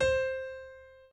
b_pianochord_v100l8-7o6c.ogg